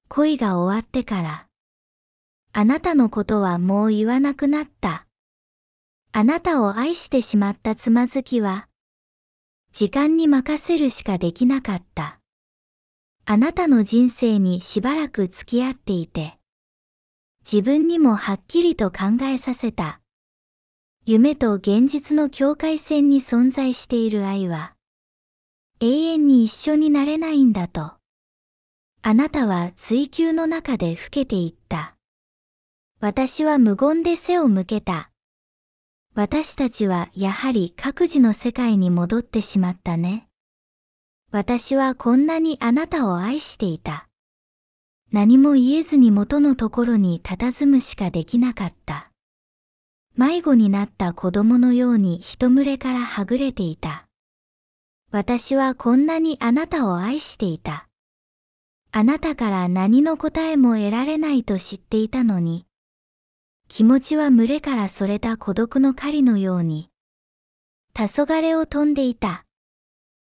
我是如此愛你日文句子朗讀.mp3